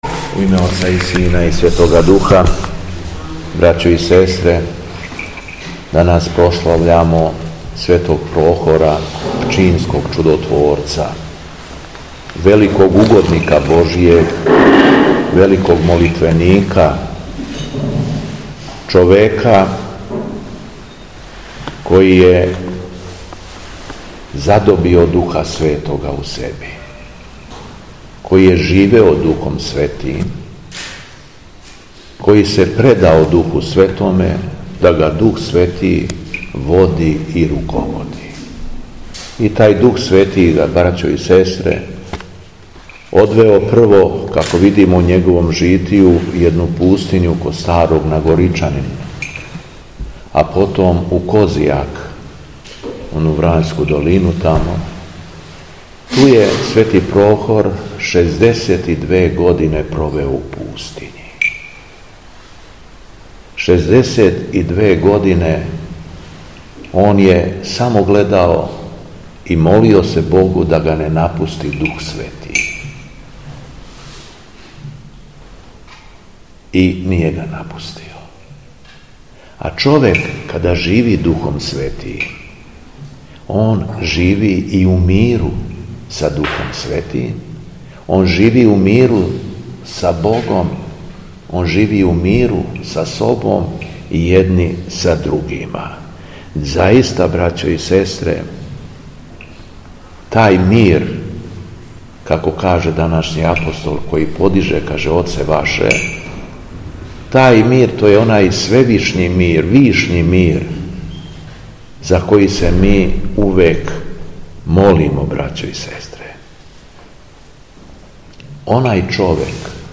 Беседа Његовог Преосвештенства Епископа шумадијског г. Јована
Епископ Јован је одржао беседу након прочитаног Јеванђеља по Луки: